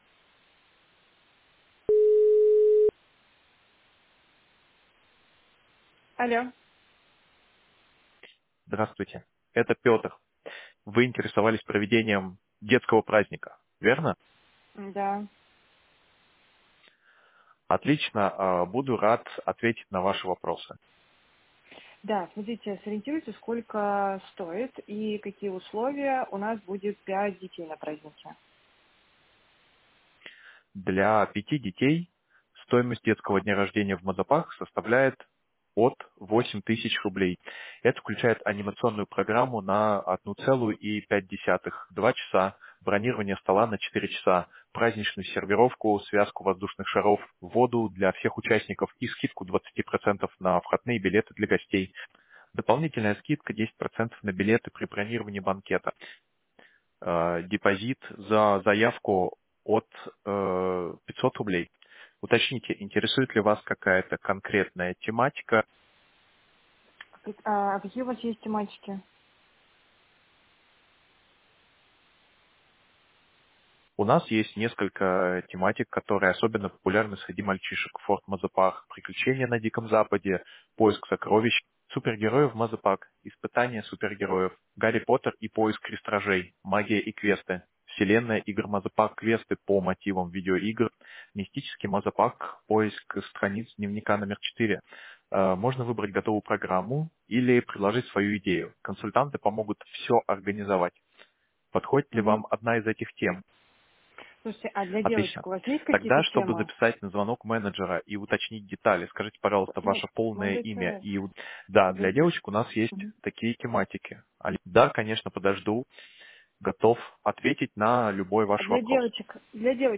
“AI-звонок”
• Очень реалистичный голос
голос Петр - организация детских праздников